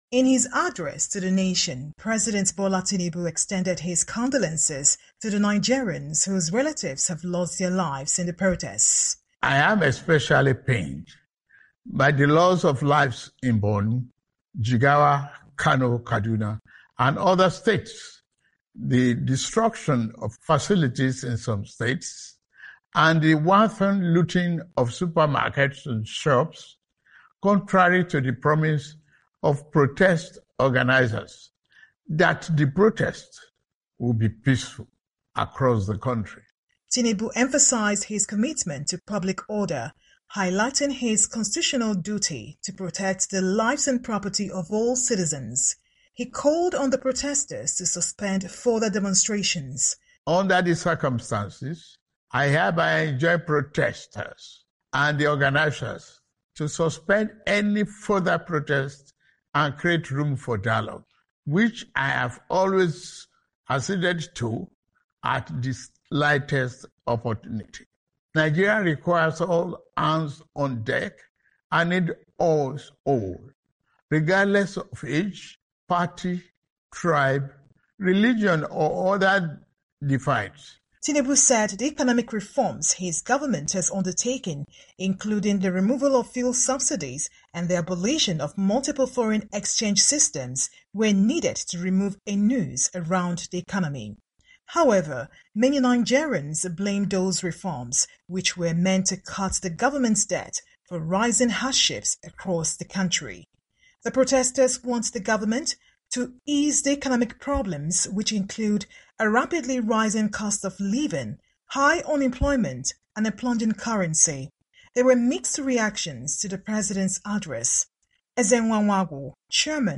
Nigeria’s President Bola Tinubu called for an end to protests that began on Thursday and urged protesters to create space for a dialogue on how to help the country. However, many Nigerians have said his comments in a televised speech Sunday did not address the demands of the protesters. Our reporter in Abuja Nigeria has more on the President's address